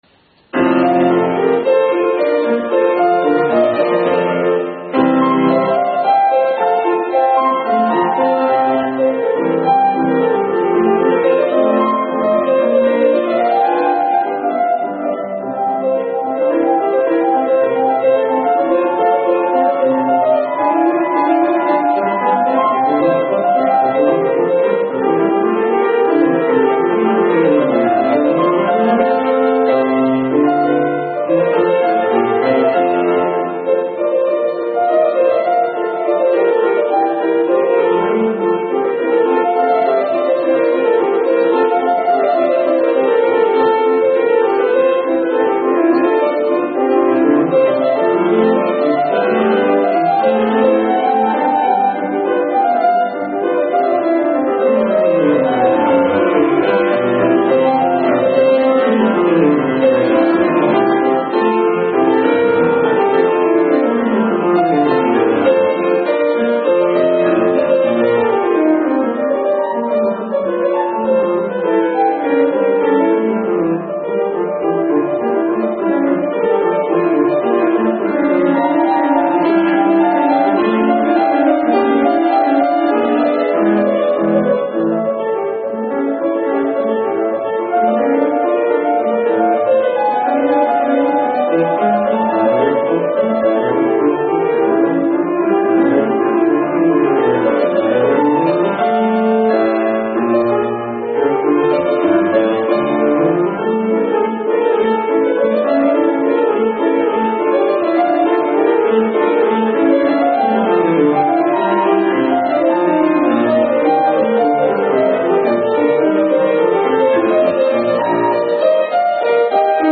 協奏曲(コンチェルト)と言いますと、通常はソロ楽器とオーケストラが一緒に演奏する作品の事を指しますが、「イタリア協奏曲 BWV.971」は、ソロ楽器のみで演奏します。
今回は、ピアノで第１楽章のみを演奏しています。